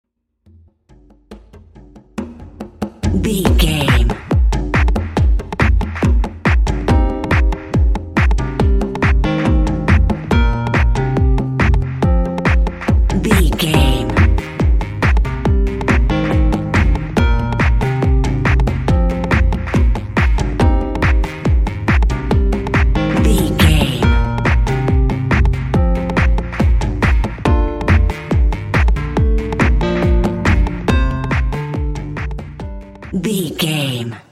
Club Percussion.
Aeolian/Minor
uplifting
futuristic
hypnotic
dreamy
drum machine
electric piano
synthesiser
house
techno
synth lead
synth bass